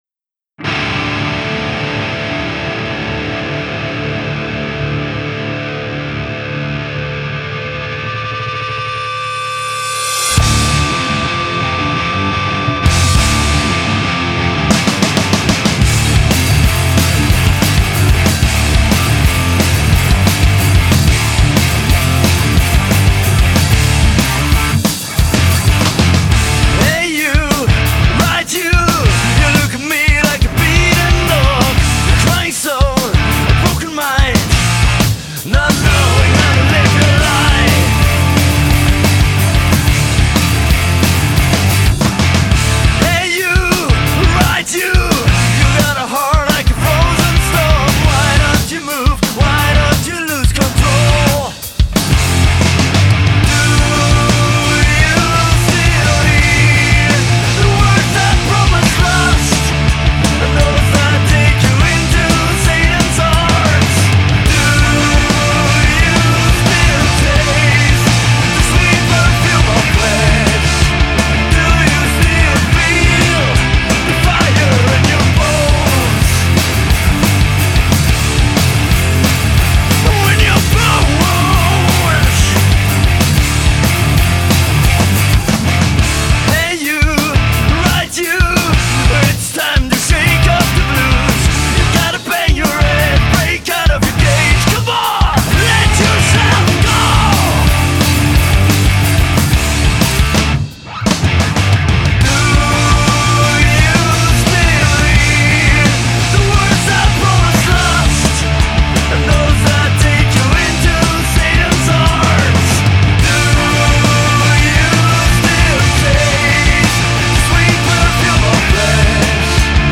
Heavy metal
Rock & Roll
Glam trash